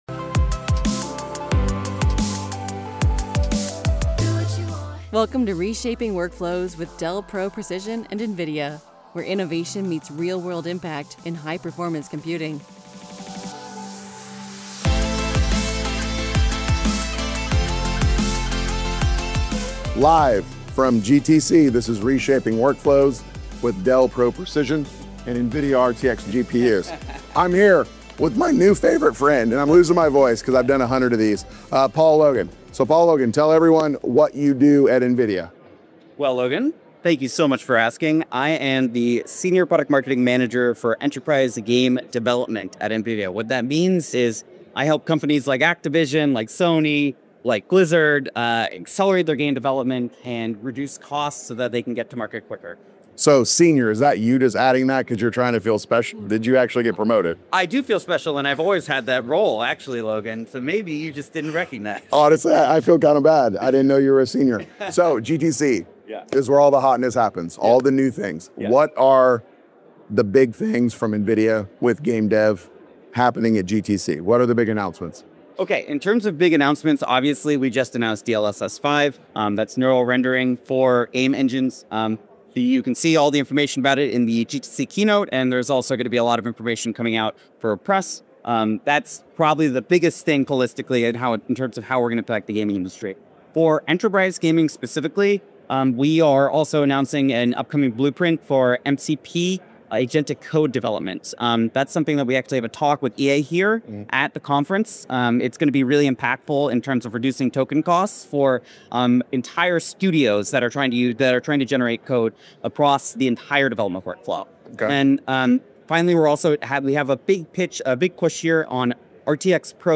Live from GTC